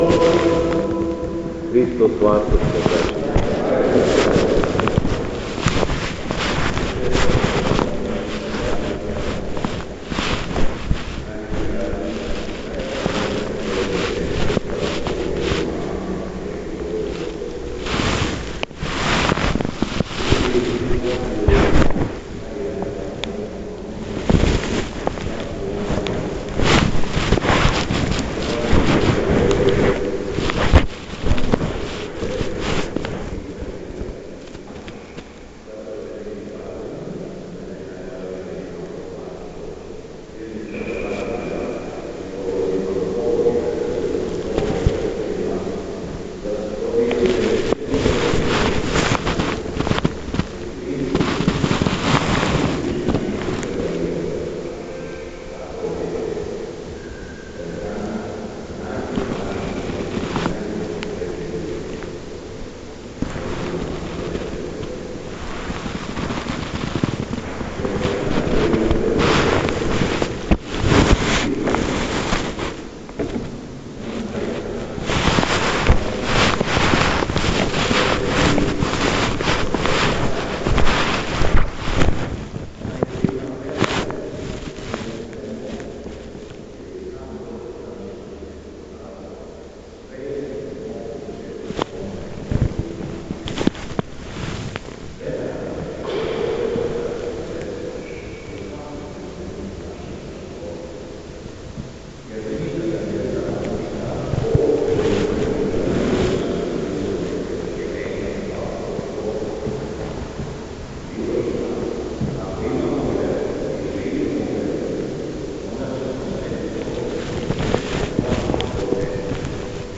Беседа Његовог Преосвештенства Епископа шумадијског господина Јована
У понедељак недеље треће недеље по Пасхи, када наша света Црква прославља Спомен јављања Часнога Крста у Јерусалиму, Његово Преосвештенство, Епископ шумадијски господин Јован, служио је свету архијерејску литургију у храму Светога Саве у крагујевачком насељу Аеродром.